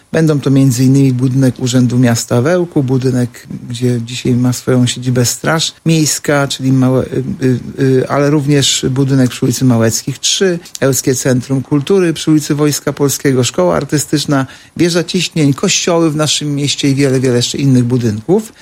Mówił Artur Urbański, zastępca prezydenta Ełku.